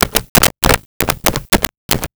Switchboard Telephone Dial 02
Switchboard Telephone Dial 02.wav